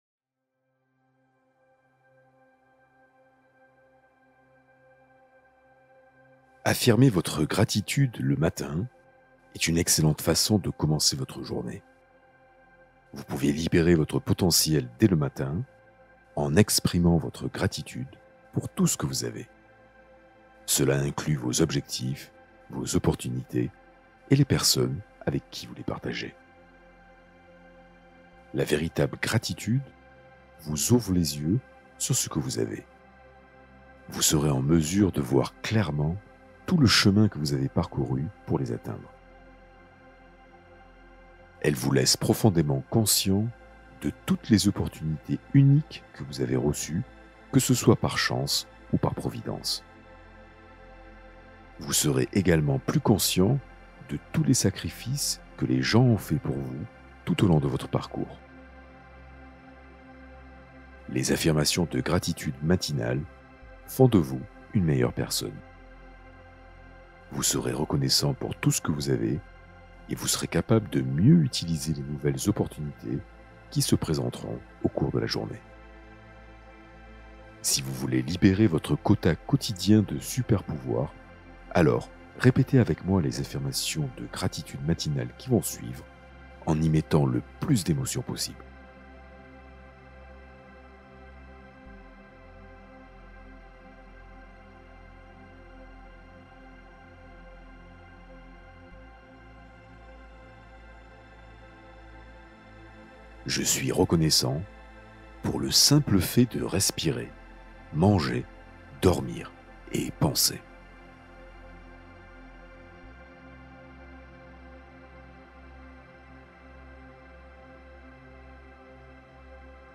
Hypnose pour renforcer optimisme et énergie positive